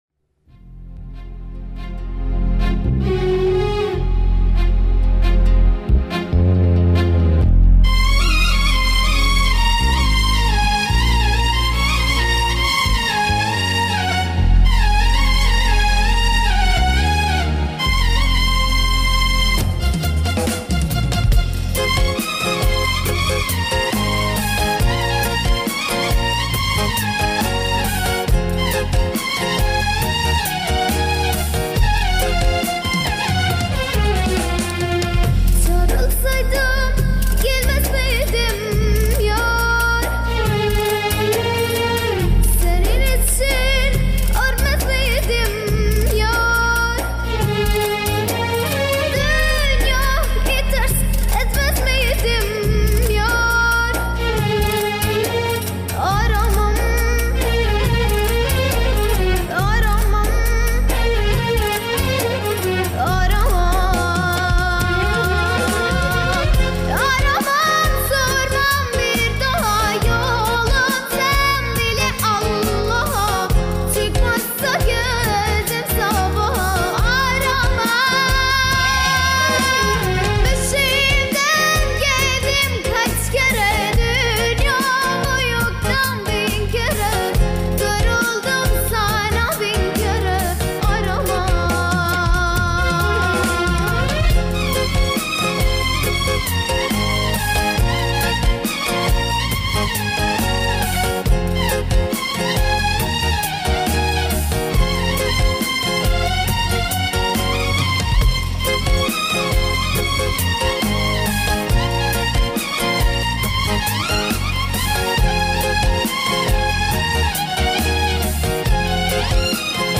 با صدای دختر جوان